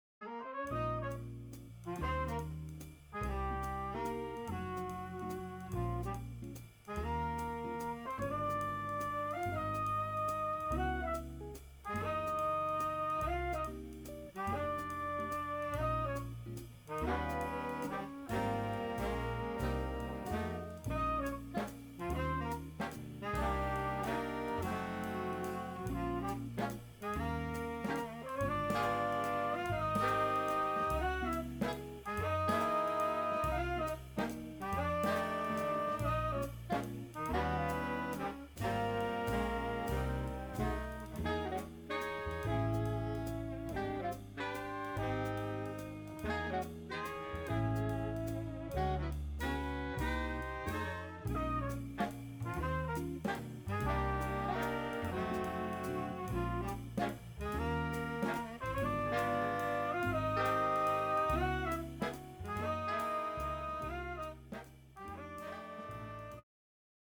Recorded Master Chord Studios January 2017